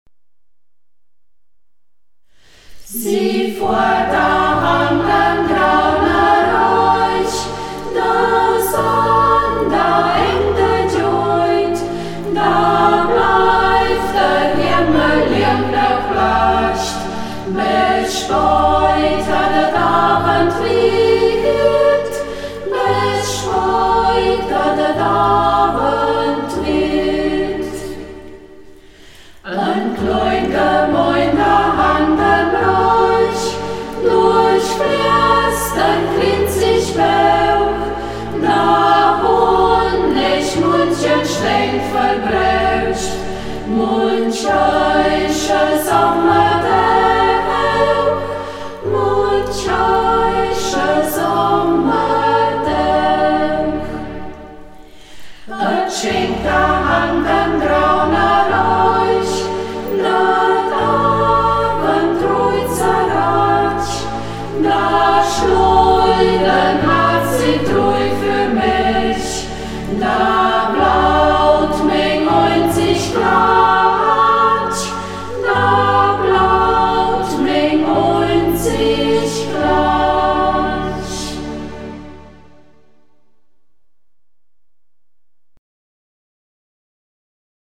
Lieder in Burgberger Mundart, gesungen von der Burgberger Singgruppe